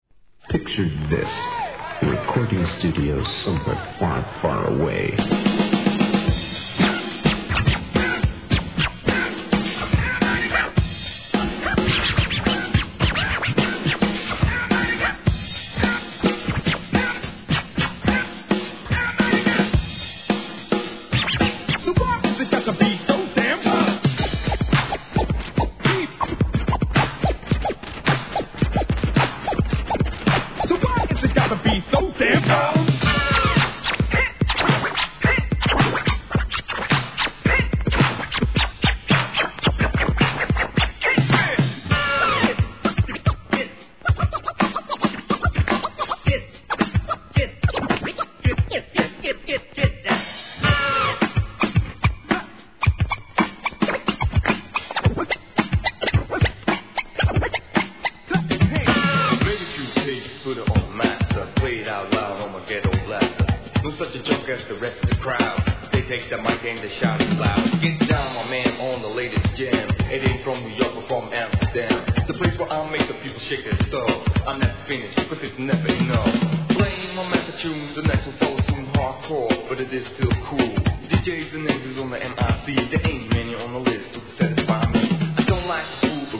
1. HIP HOP/R&B
1989年、OLD SCHOOL、ダンクラ定番の大ネタが満載の大阪発、激熱MEGA MIX物!!